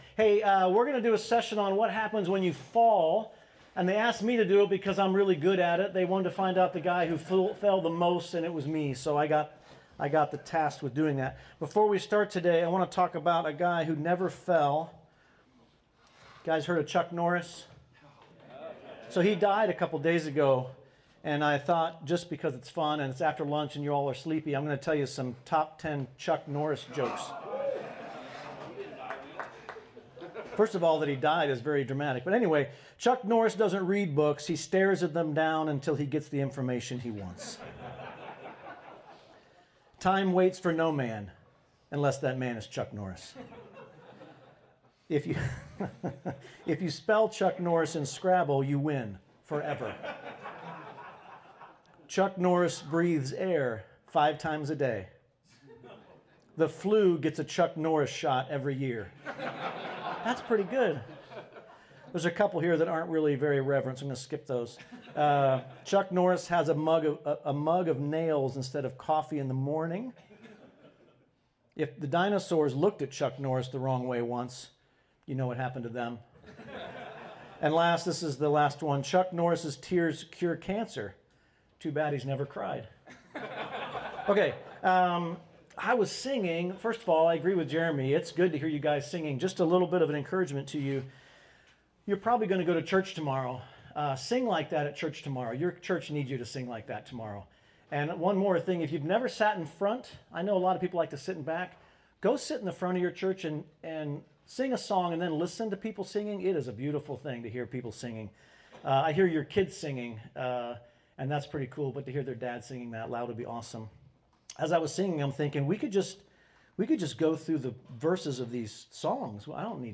Session 3 of the Faithful Masculinity Retreat